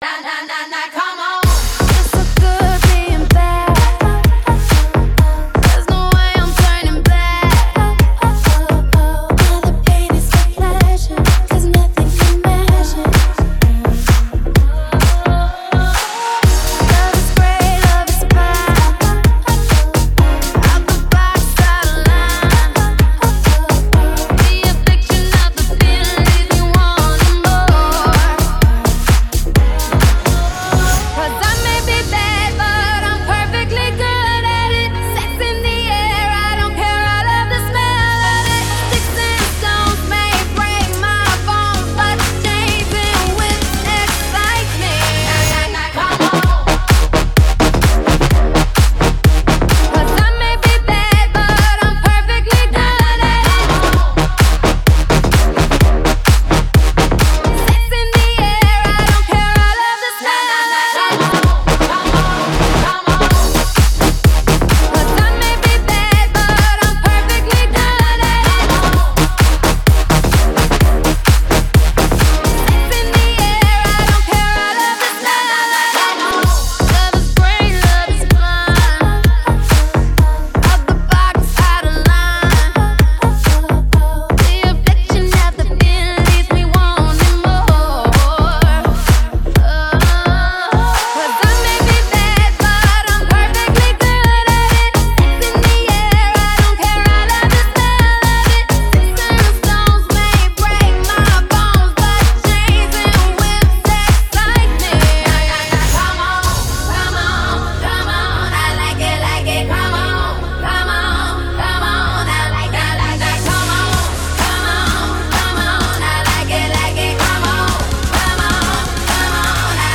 Жанр: Club, Dance, Other